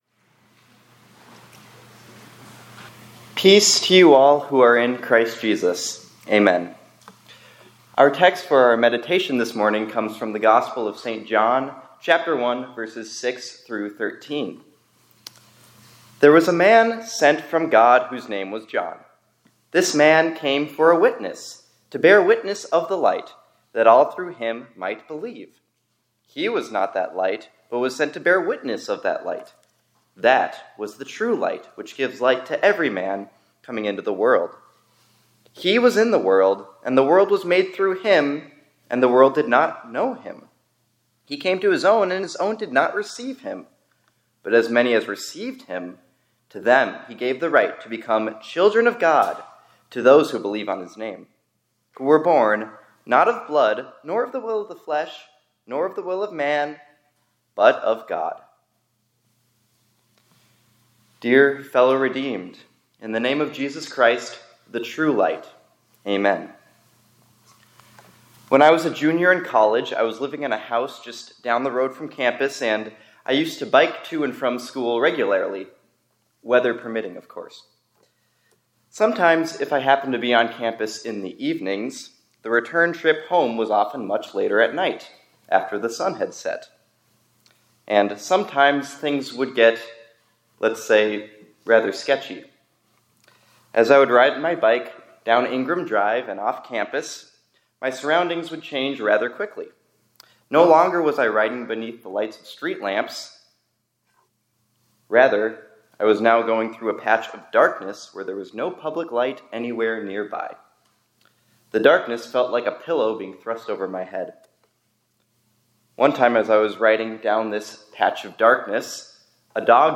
2025-12-15 ILC Chapel — The Light of the World Has Power to Transform